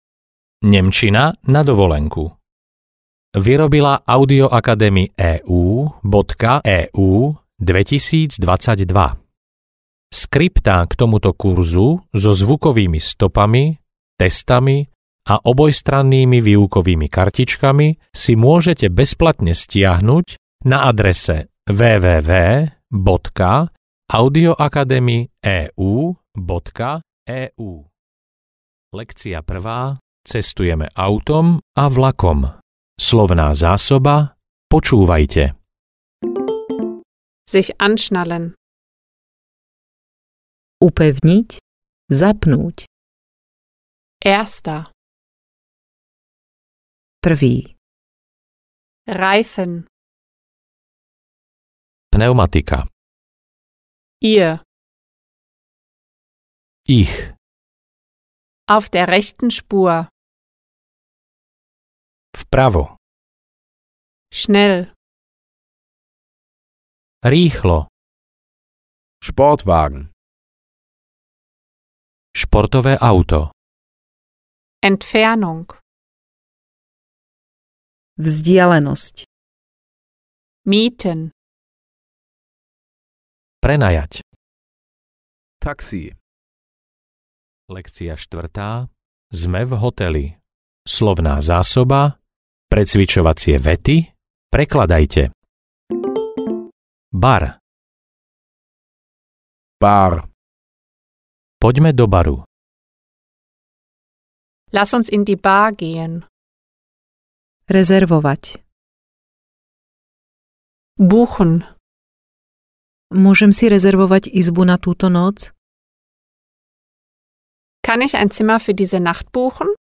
Nemčina na dovolenku A1-B1 audiokniha
Ukázka z knihy